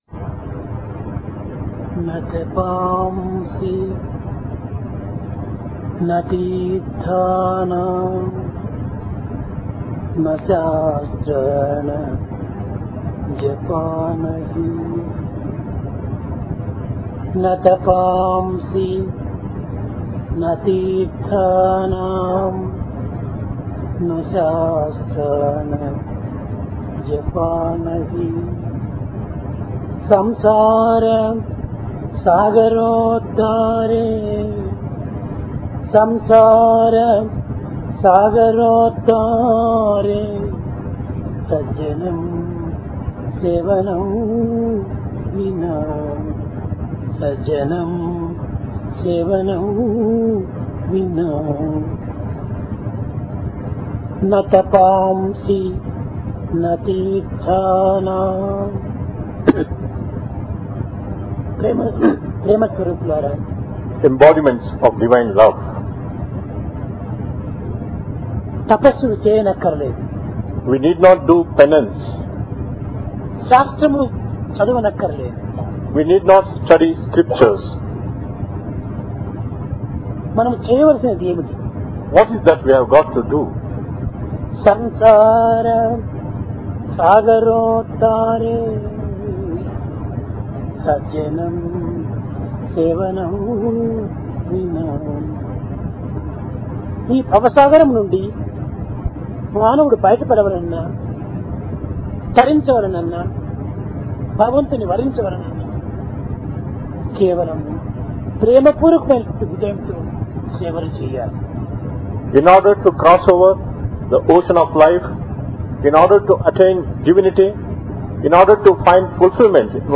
Discourse